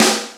Index of /90_sSampleCDs/Roland - Rhythm Section/SNR_Snares 7/SNR_Sn Modules 7